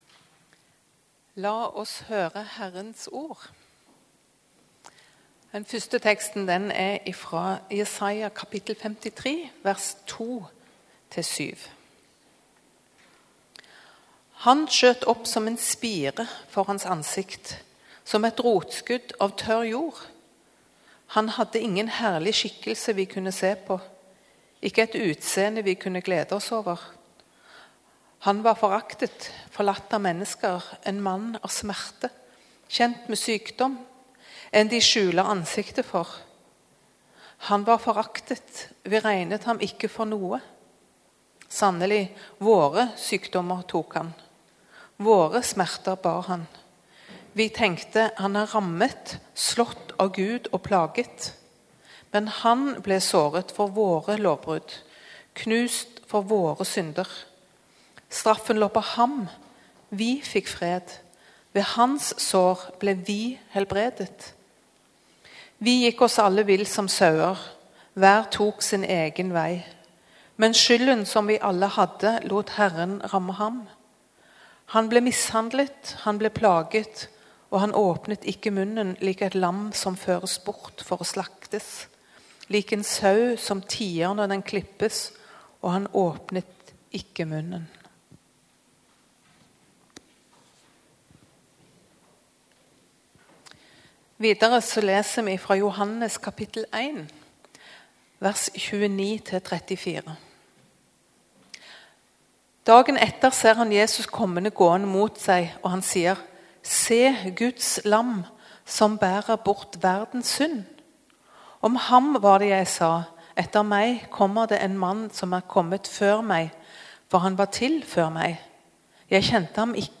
Gudstjeneste 1. oktober 2023, frelser - offerlam | Storsalen